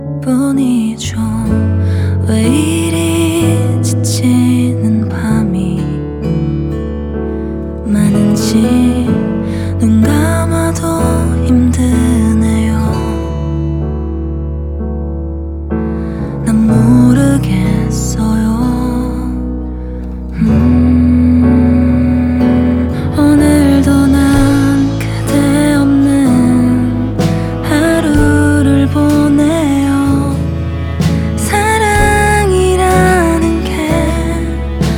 2025-10-29 Жанр: Соундтрэки Длительность